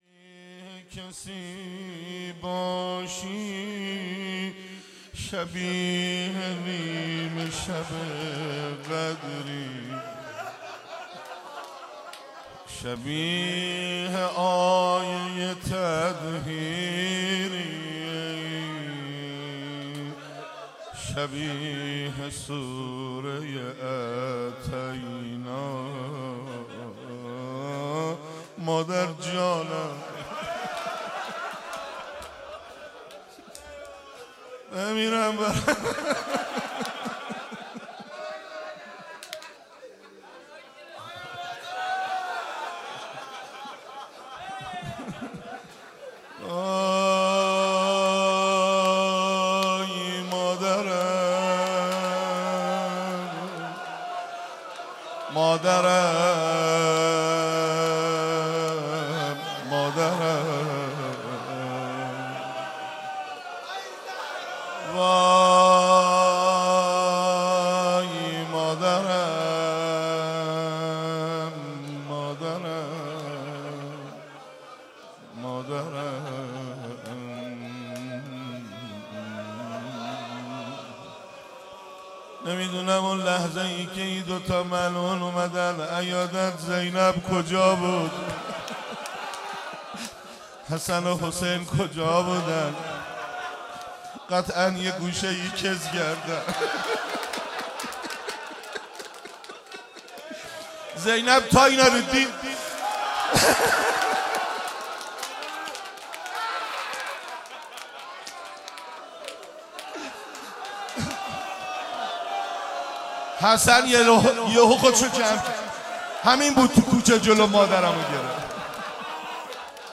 روضه
ایام فاطمیه1393-روز دوم.mp3